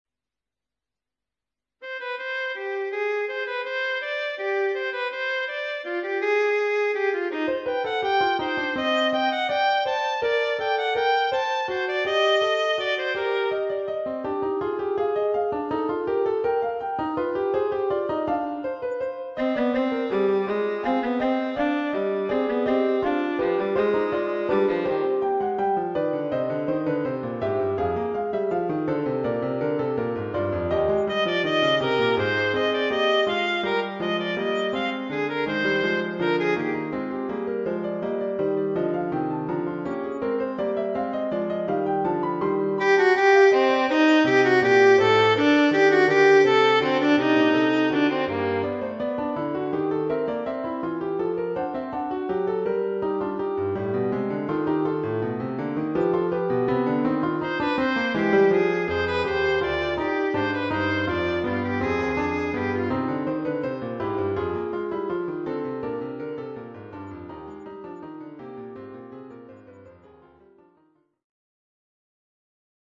› Themeneinsätze nacheinander einsetzen [MP 3 |352 KB]
Struktur-Fuge-im-MIDI-So001.mp3